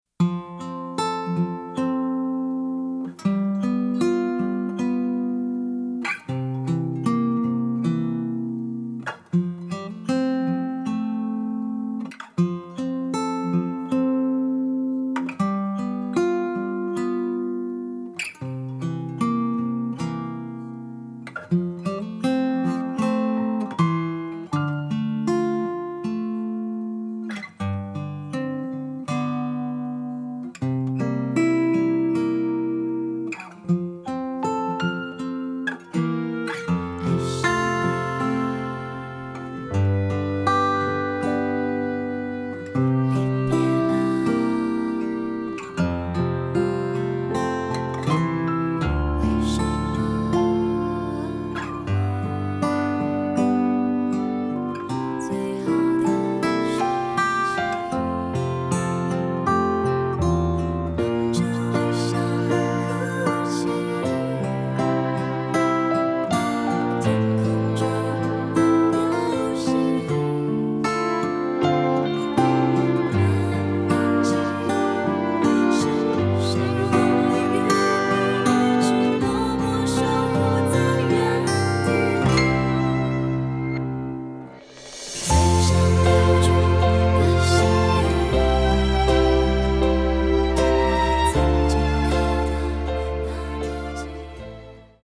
伴奏信息
歌曲调式：F调